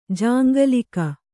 ♪ jāŋgalika